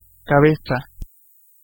Ääntäminen
IPA : /ˈhɛd/